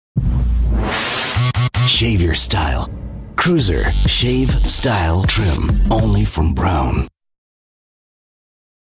mid-atlantic
Sprechprobe: Werbung (Muttersprache):
The warm but serious tone of his voice lends itself to many situations, where an American is required to speak in a " European" context.